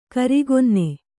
♪ karigomme